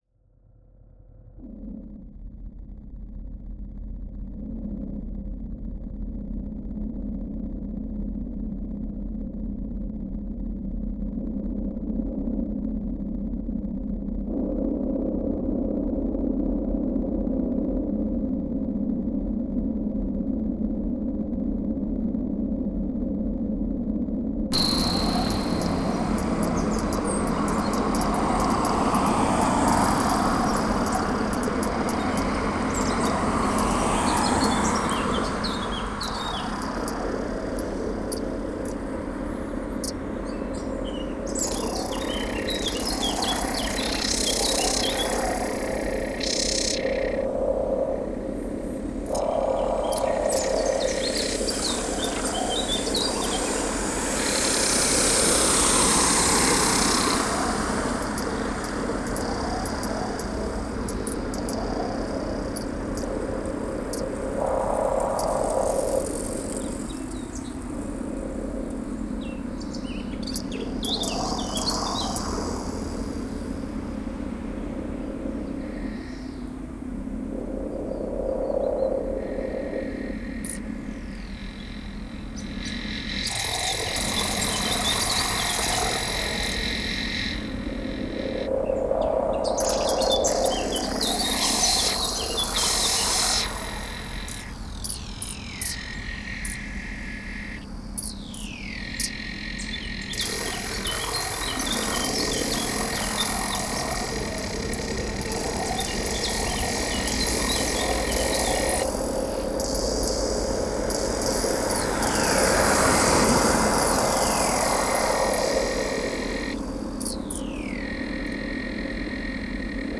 An early morning walk at the beginning of June, was a way to prove to myself that the world hadn’t changed all that much to me and the perceived calm was maybe just a blip on the sonic map.
The birdsong that was so prominent to my ear, was in competition with the increasing passing traffic on the dual carriageway and the site machinery on the roadworks. Sirens too….and the static, the hum, the unseen soundwaves were still there.
The birdsong was not as loud and clear. It was masked and in competition.
Taking just the one recording, using all this digital information,  I created what turned out to be a very noisy track.
a-quiet-field-wav.wav